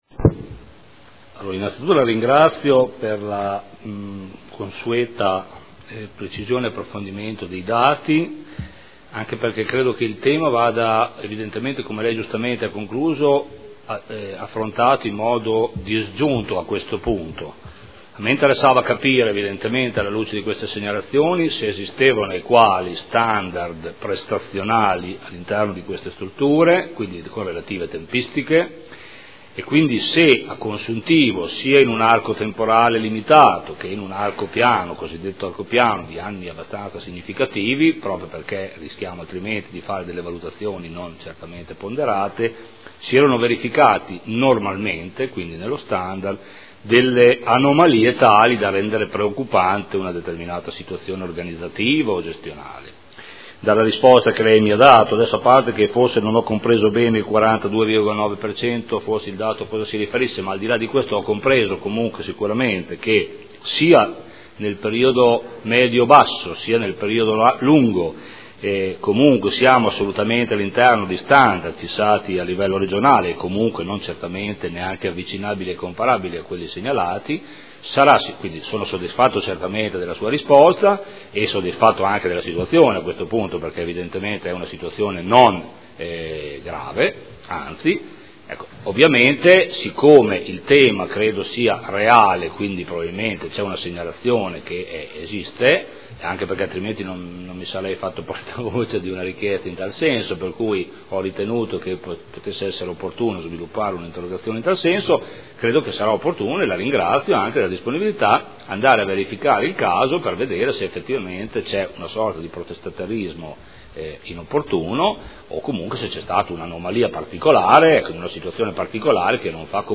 Seduta del 27/09/2012.